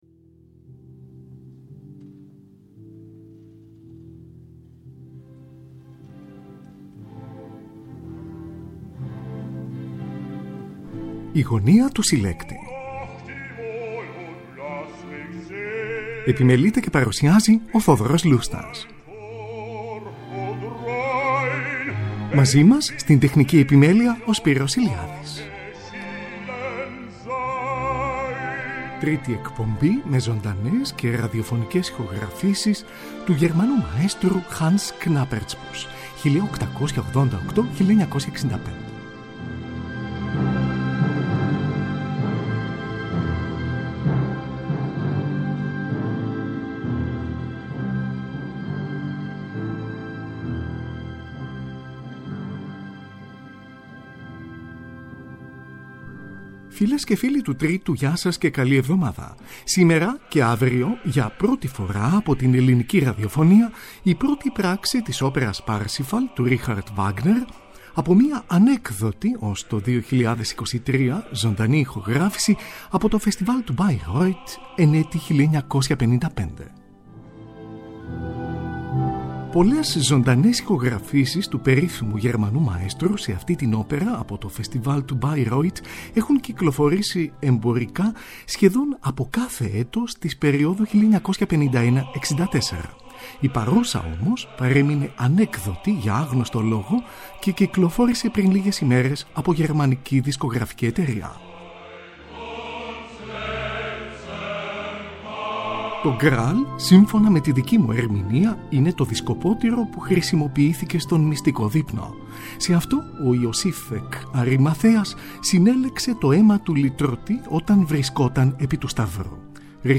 Τρίτη εκπομπή με ζωντανές και ραδιοφωνικές ηχογραφήσεις του μαέστρου HANS KNAPPERTSBUSCH (1888-1965)